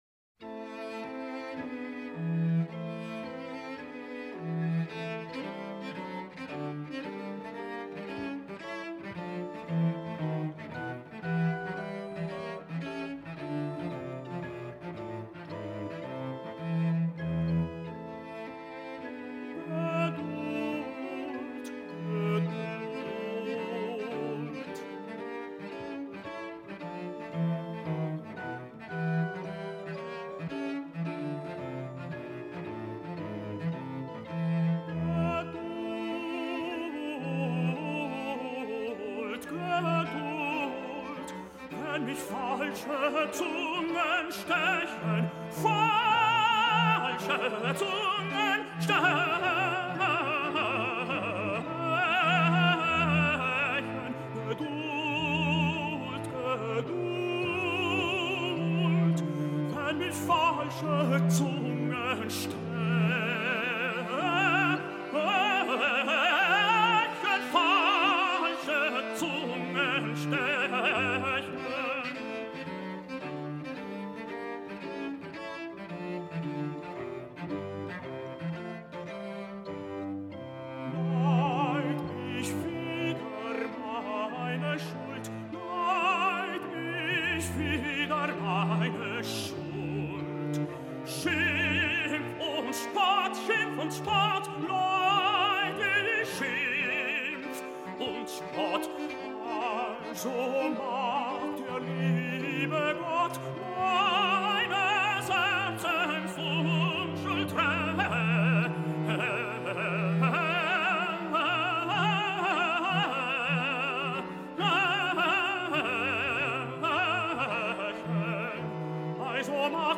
08-geduld-arie-tenor.mp3